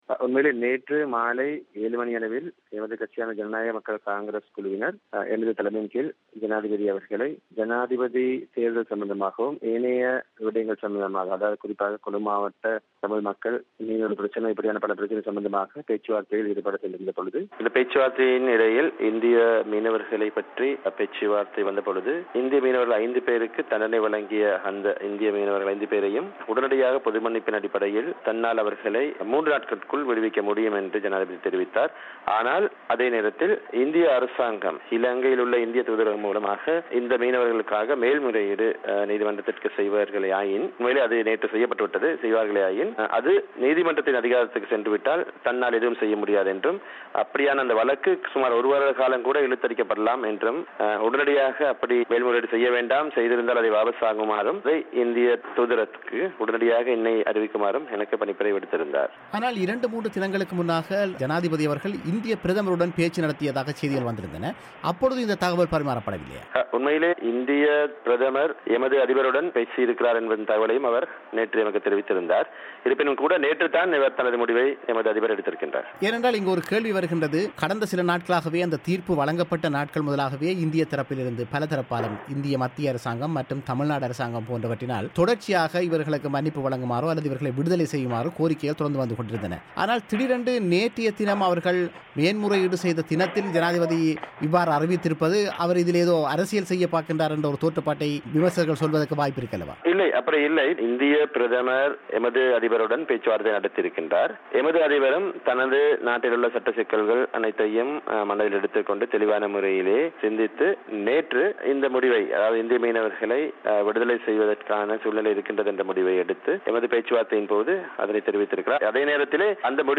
இது குறித்த அவரது செவ்வியை நேயர்கள் இங்கு கேட்கலாம்.